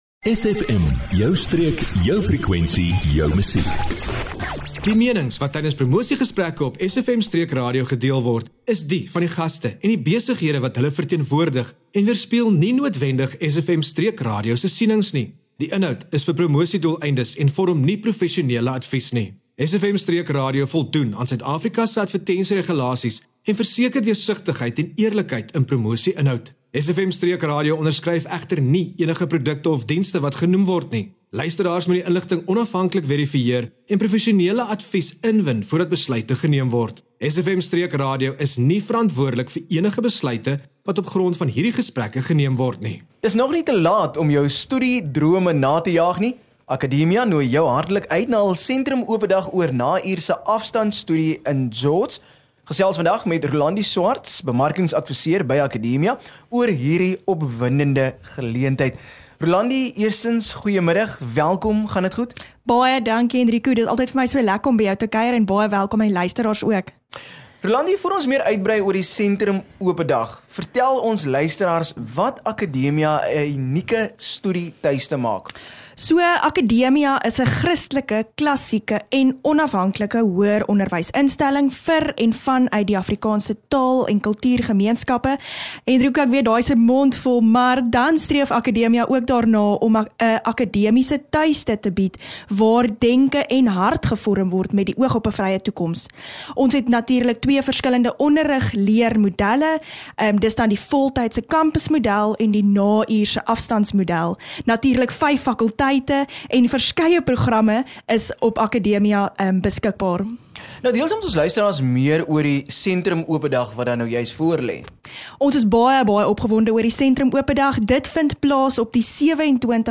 SFM Allegaartjie van onderhoude